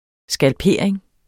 Udtale [ sgalˈpeɐ̯ˀeŋ ]